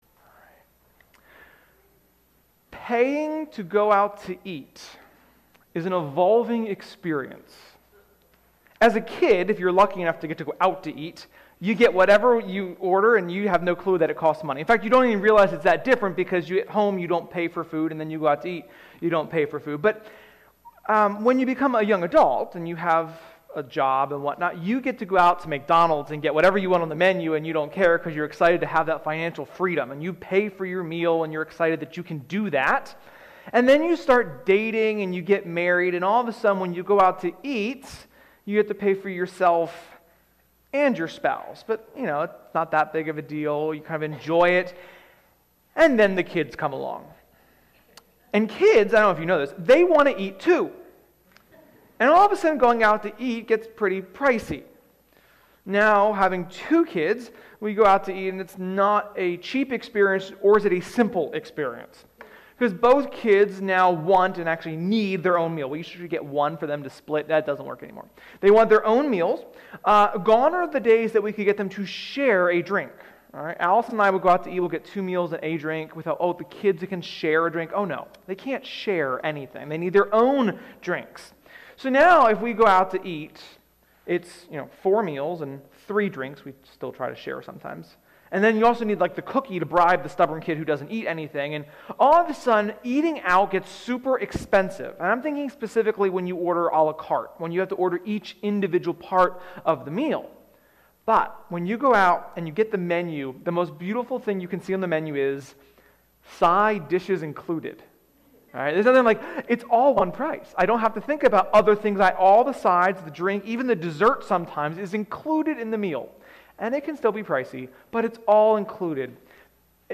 Sermon-5.23.21.mp3